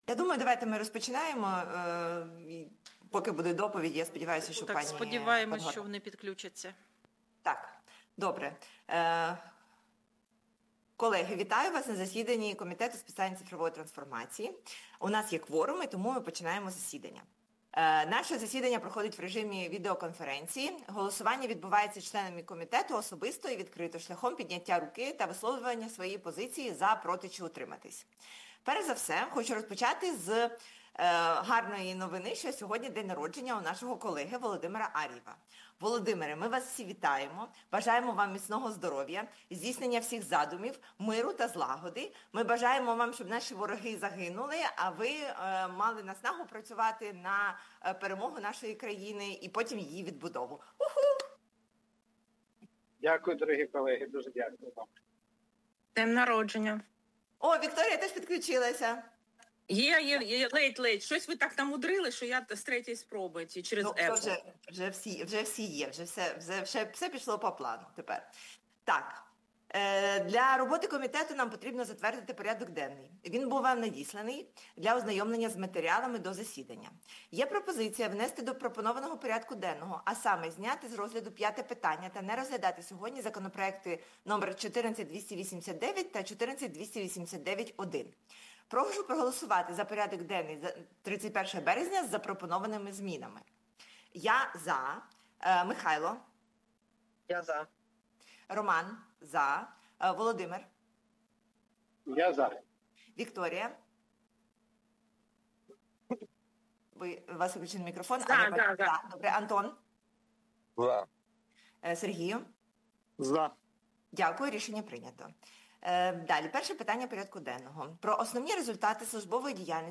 Аудіозапис засідання Комітету від 31.03.2026